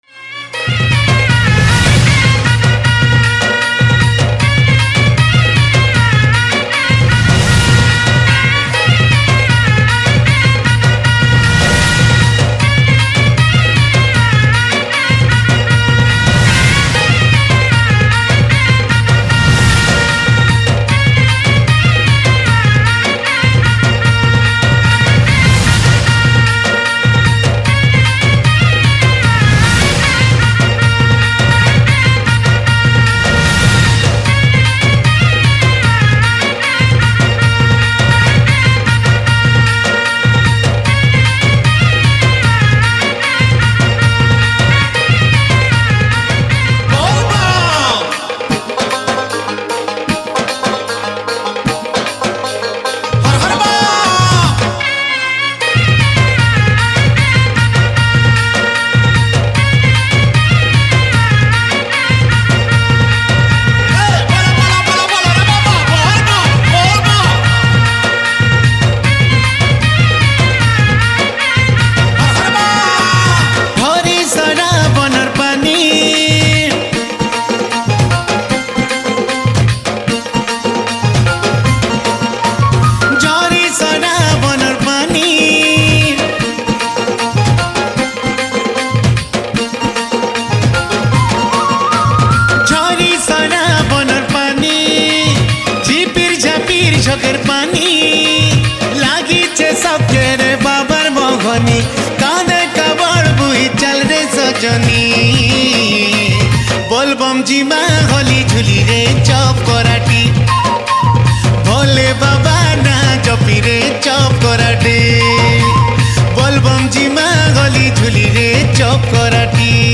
Bolbum Special Song